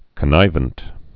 (kə-nīvənt)